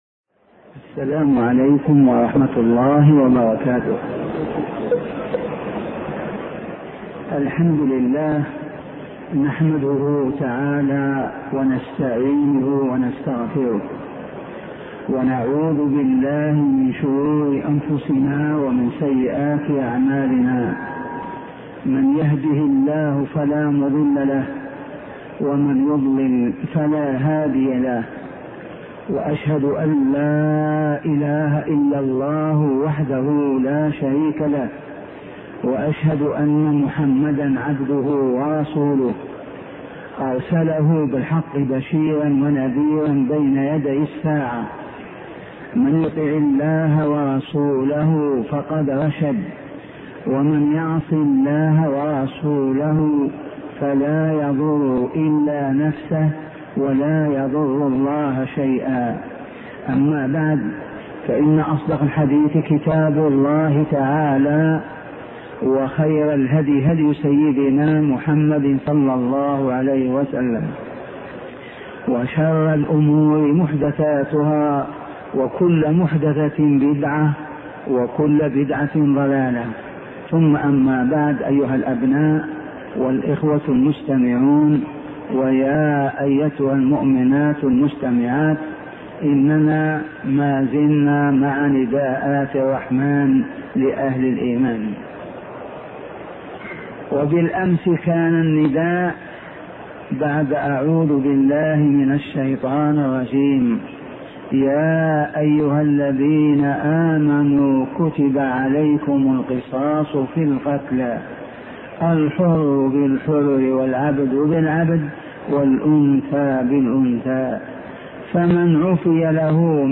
شبكة المعرفة الإسلامية | الدروس | نداءات الرحمن لأهل الإيمان 005 |أبوبكر الجزائري